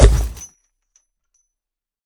sounds / mob / ravager / step2.ogg